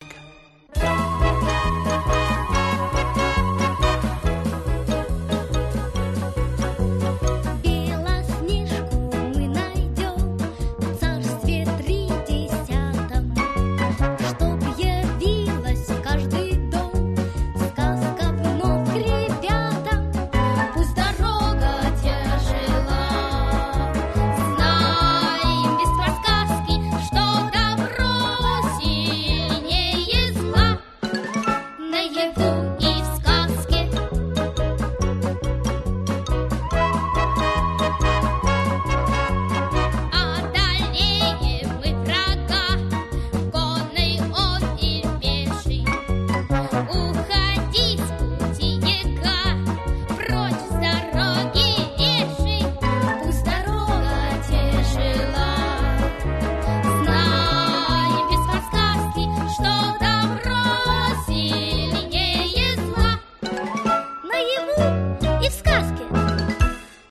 динамичная и походная.